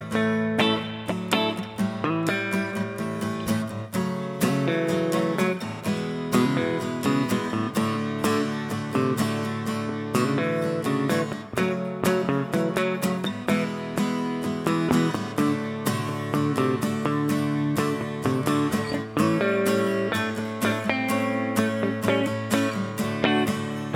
Guitars Only Mix Rock 3:46 Buy £1.50